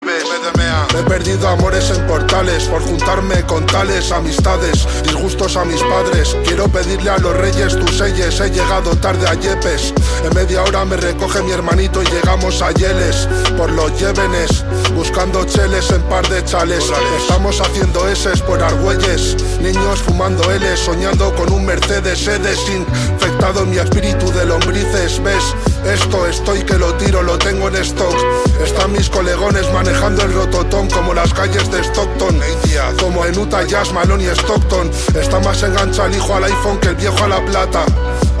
Categoría Rap